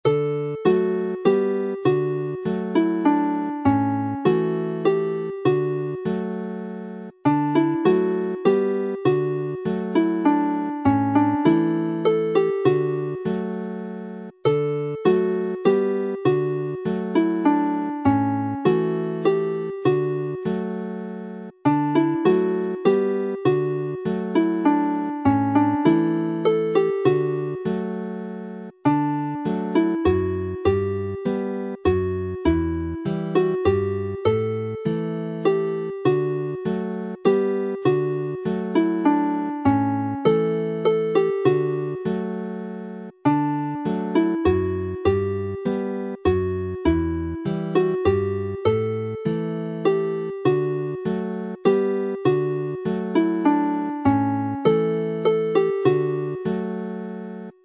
This time the set is pitched in G major and D major which go well on the fiddle and D whistle and harmonies have been included for each tune.